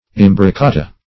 Search Result for " imbrocata" : The Collaborative International Dictionary of English v.0.48: Imbrocata \Im`bro*ca"ta\, Imbroccata \Im`broc*ca"ta\, n. [It. imbroccata.] A hit or thrust.